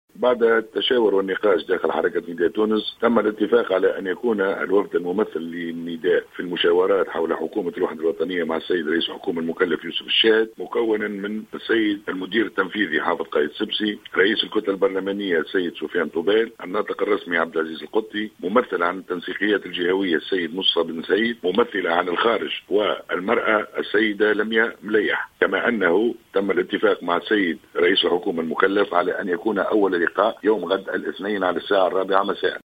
في تصريح للجوهرة اف ام